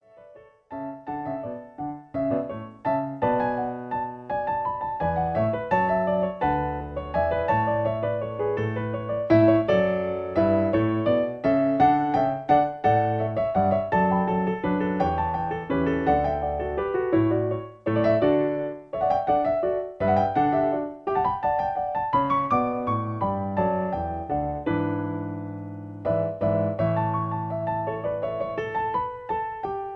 In D. Piano Accompaniment. Chorus